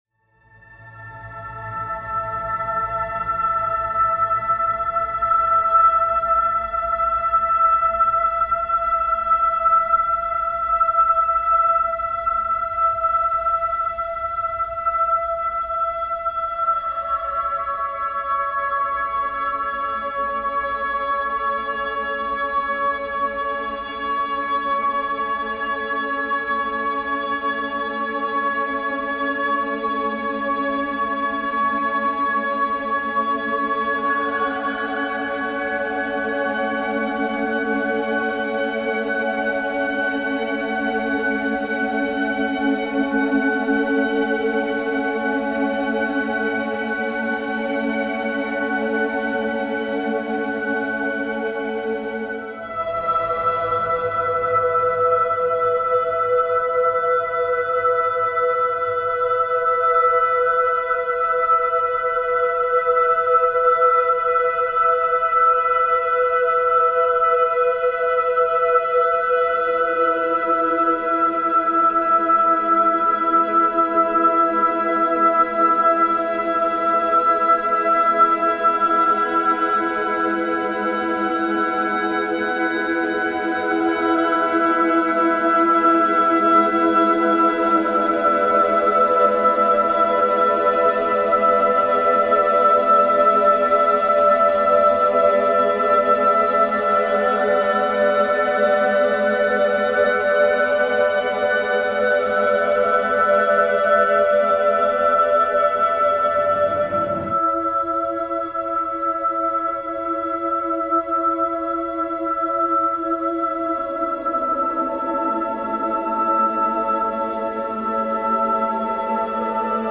Pièce sonore